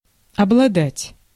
Ääntäminen
stressed: IPA : /hæv/ US : IPA : [hæv]